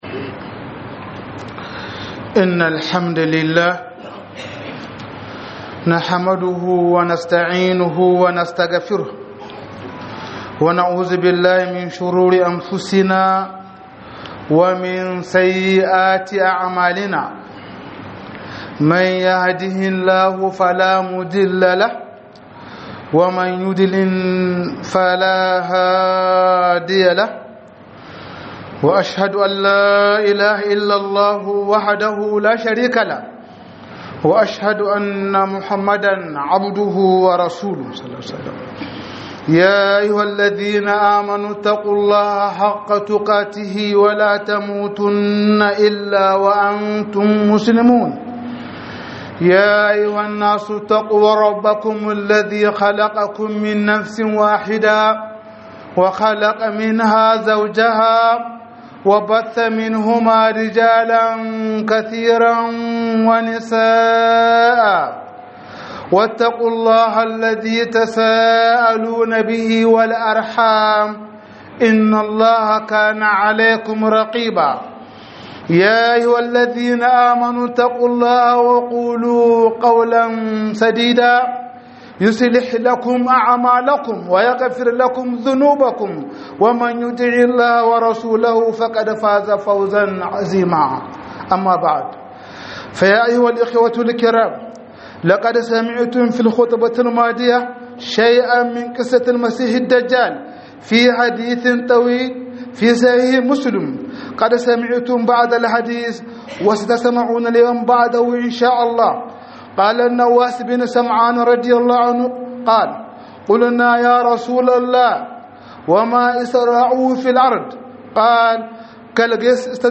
009 Dujal O2 - HUDUBA